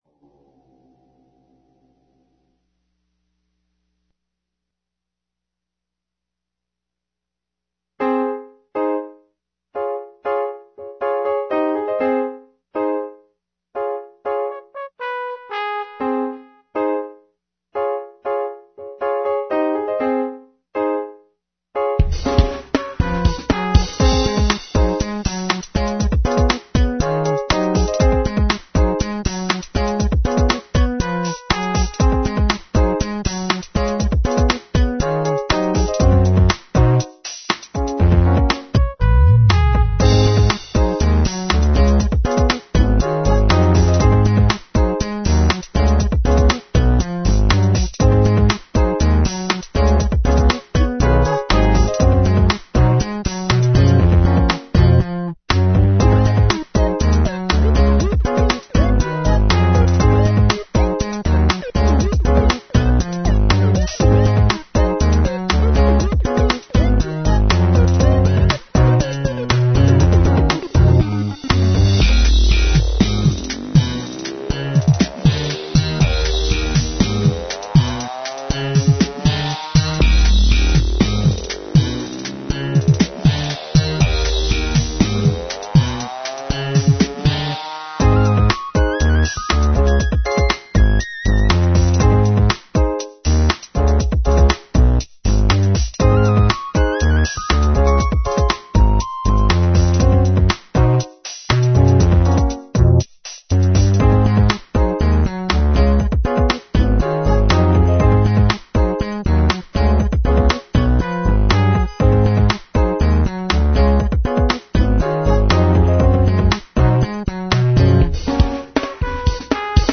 dance/electronic
Breaks & beats
Trip-hop